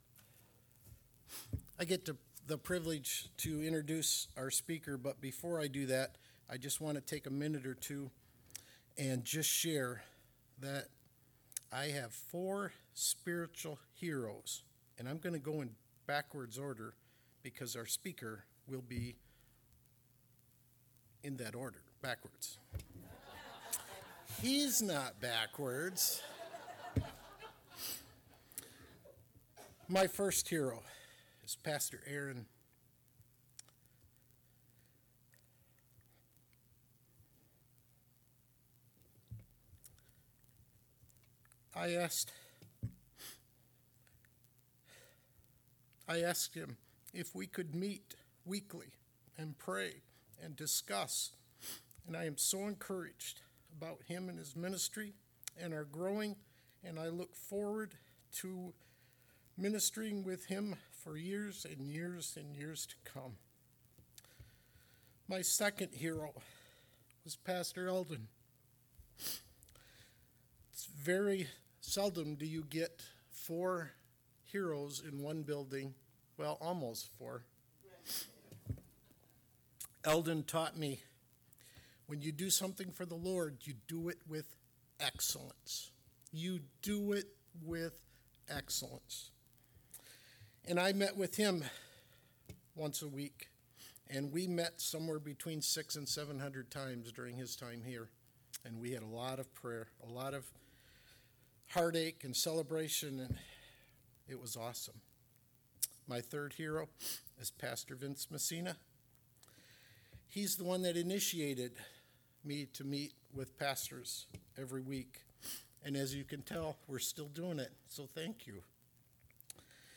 Sermon 9-24-17.mp3